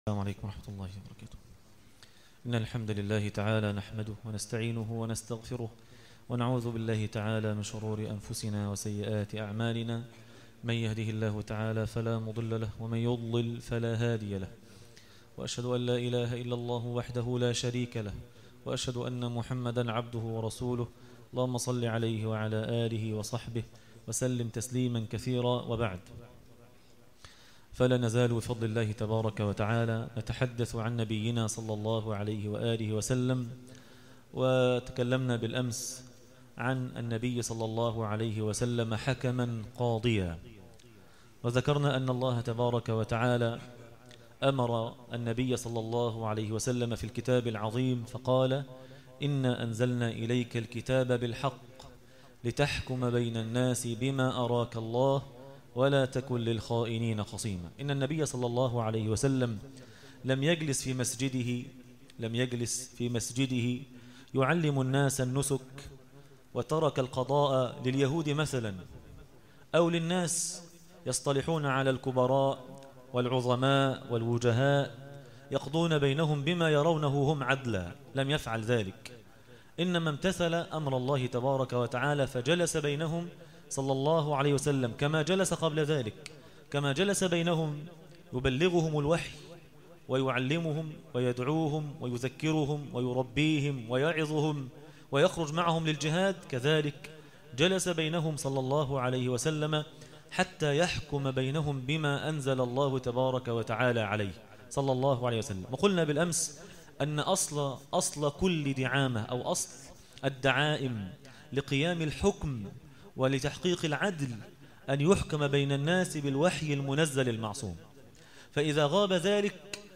درس التراويح